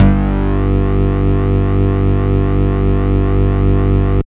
29 BASS   -R.wav